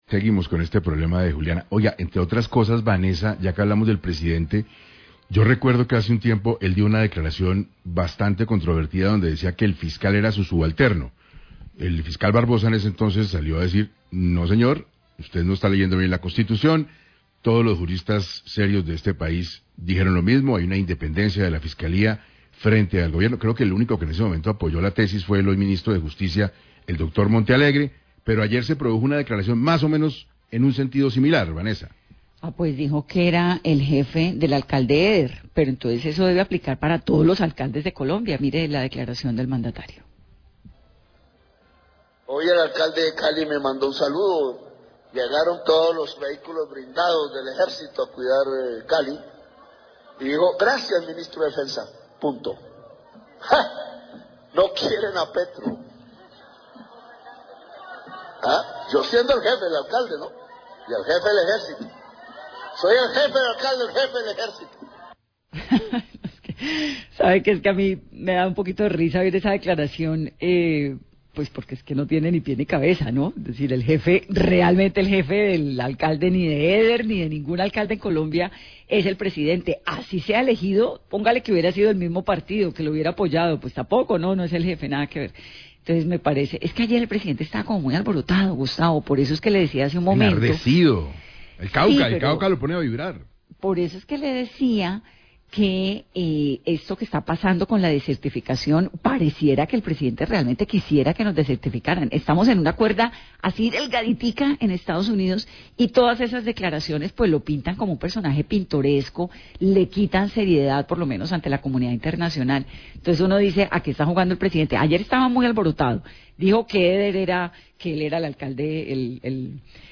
Reproducen audio del presidente Petro afirmando que el alcalde Eder no le agradeció por el envio de los vehículos blindados Hunter y que él era su jefe. Periodista Vanessa de la Torre critica estas declaraciones destempladas de Petro.